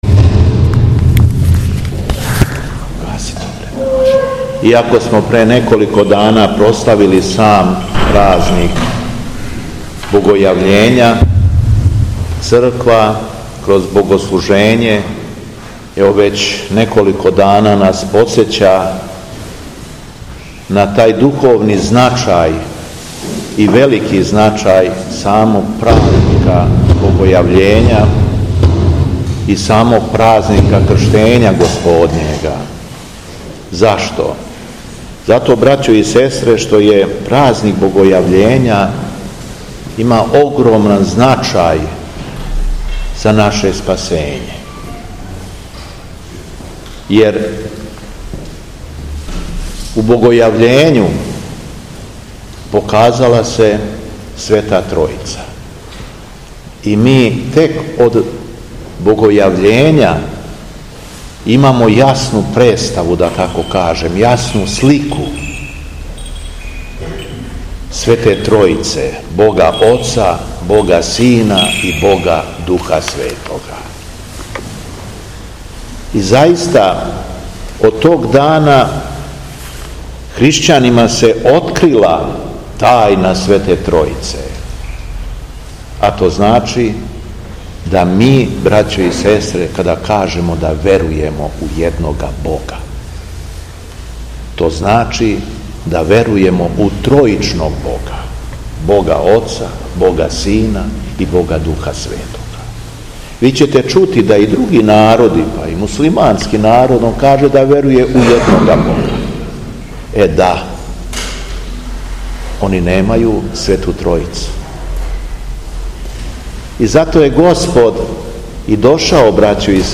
СВЕТА АРХИЈЕРЕЈСКА ЛИТУРГИЈА У ХРАМУ СВЕТЕ ПЕТКЕ У СМЕДЕРЕВСКОЈ ПАЛАНЦИ - Епархија Шумадијска
Беседа Његовог Преосвештенства Епископа шумадијског Г. Јована
После прочитаног Јеванђељског зачала Владика се обратио окупљеном народу богонадахнутом беседом.